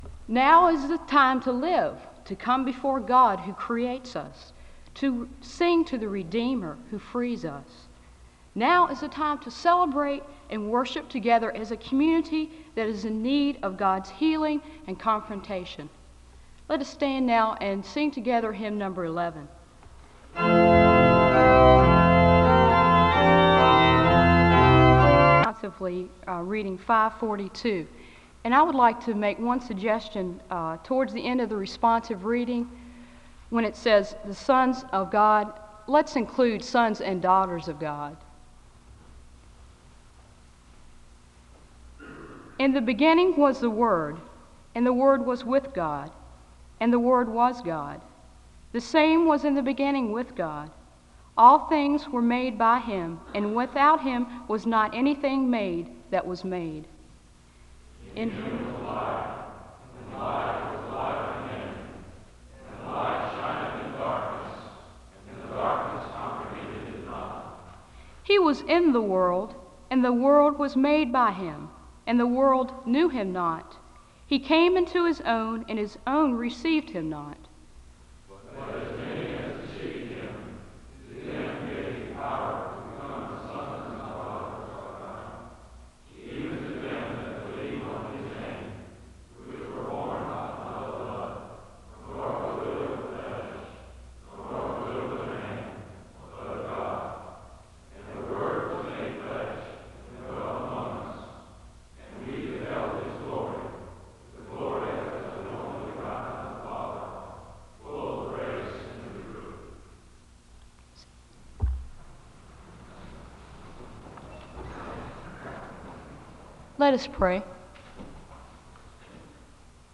The speaker and audience read from John 1:1-14, and the speaker gives a word of prayer (00:00-02:24). The choir leads in a song of worship (02:25-06:57).